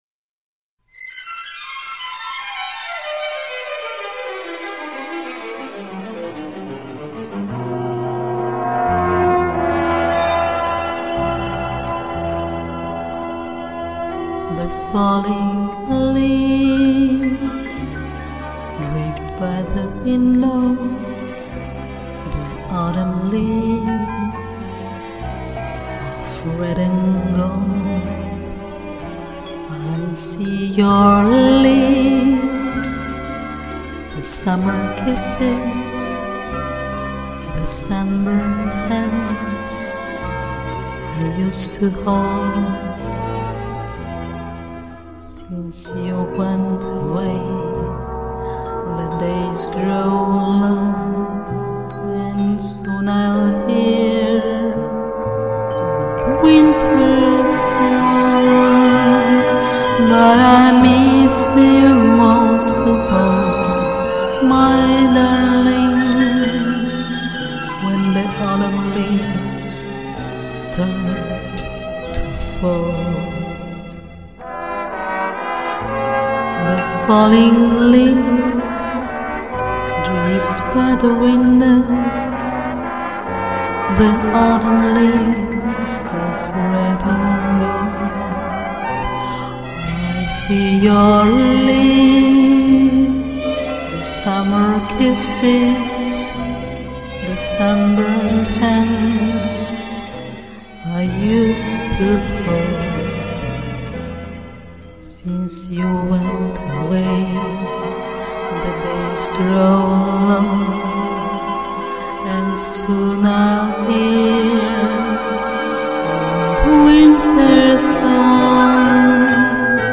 трепетно и очень красиво спела!